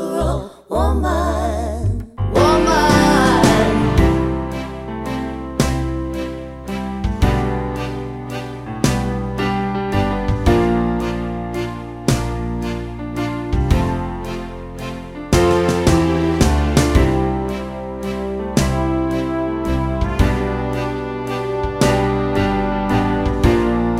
no Backing Vocals Soul / Motown 2:58 Buy £1.50